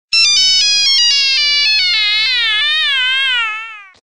Nokia Dying Sound Effect Free Download